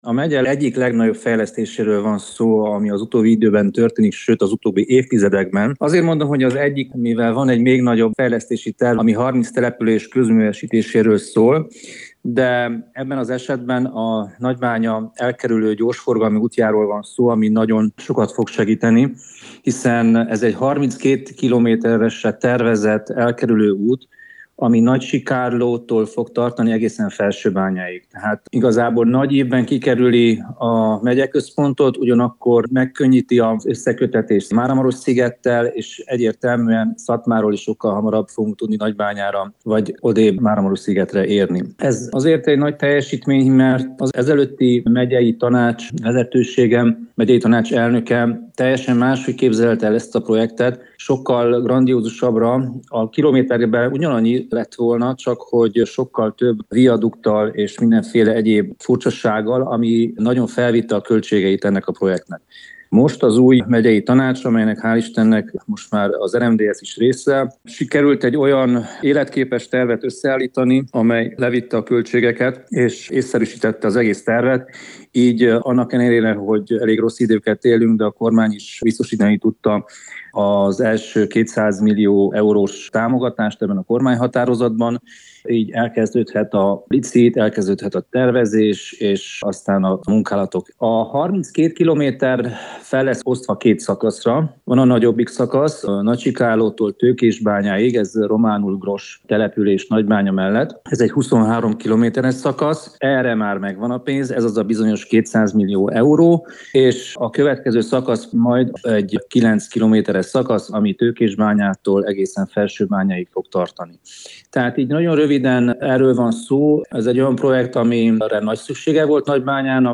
A projektet európai uniós forrásból és az állami költségvetésből finanszírozzák – számolt be róla rádiónknak az RMDSZ Máramaros megyei tanácsosa, Pintér Zsolt.